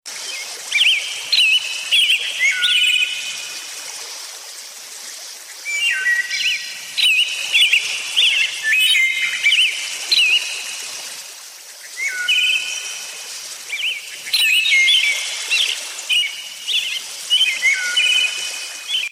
773_birds-and-water.mp3